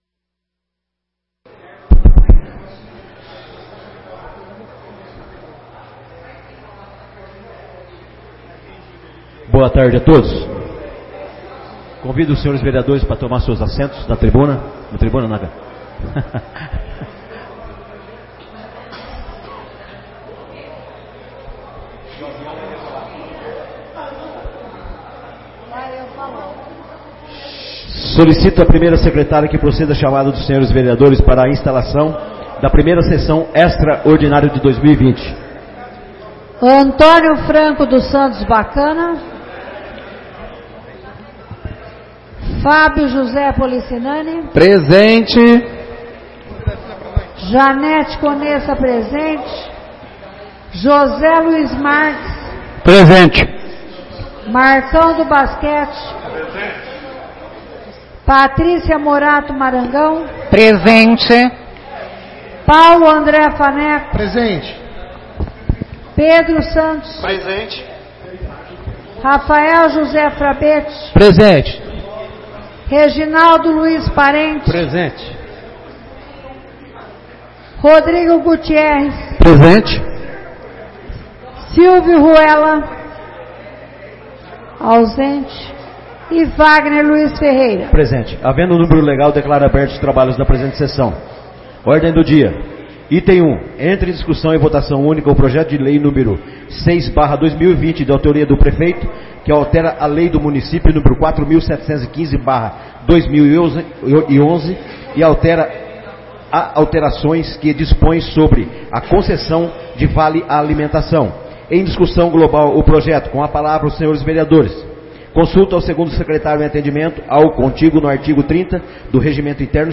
1ª Sessão Extraordinária de 2020